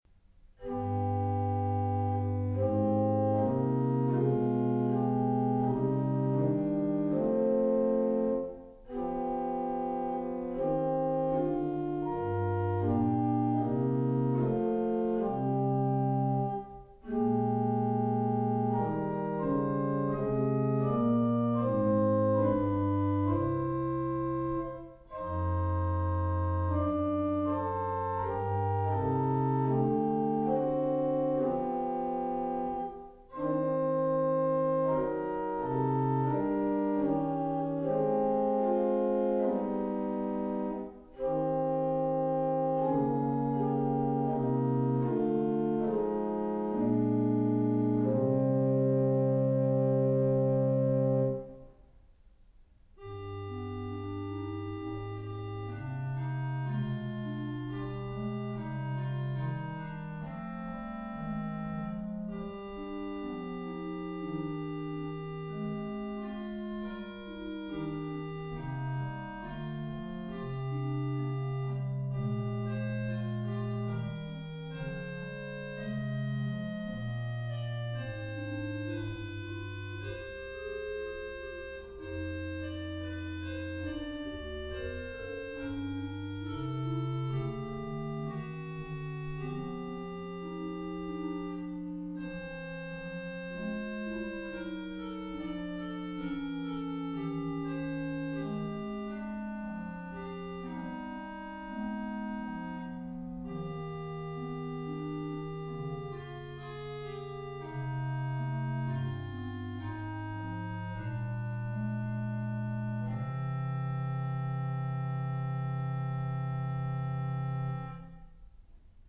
Orgel: -orgelkoraler 3st. koral og variation